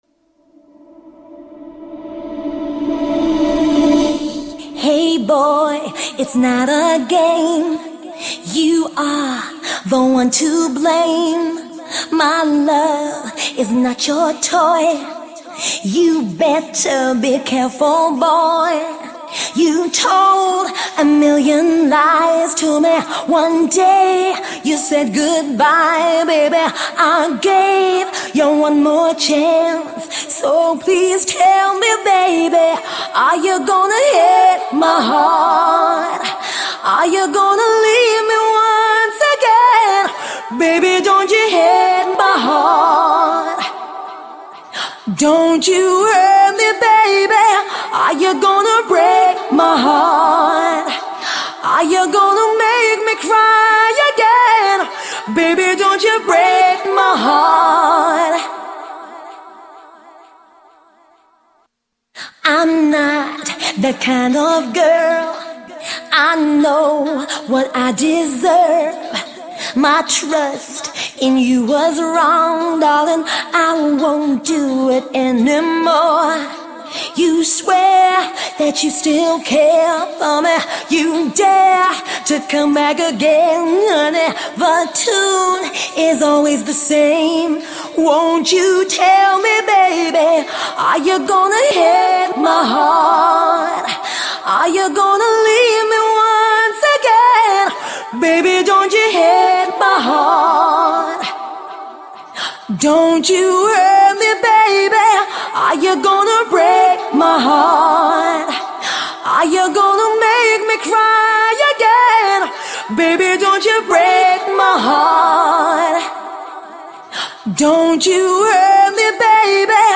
Категория: Скачать Зарубежные акапеллы